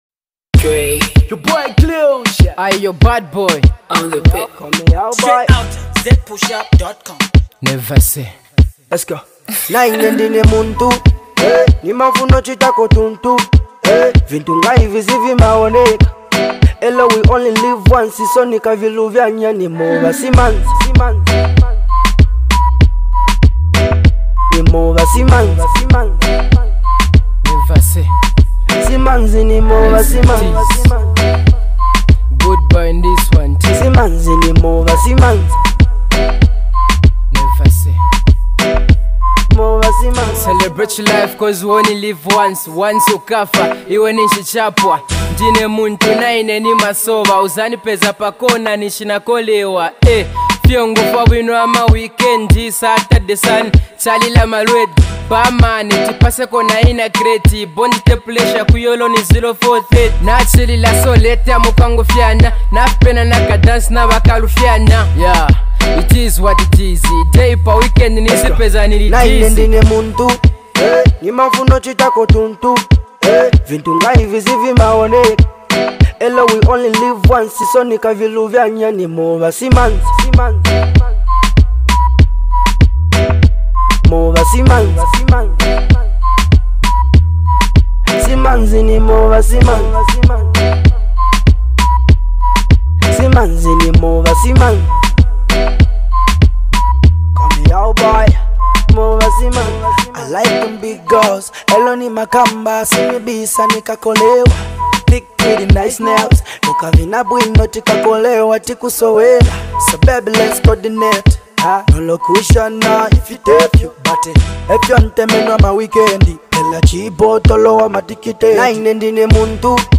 the song is dedicated to those who love to dance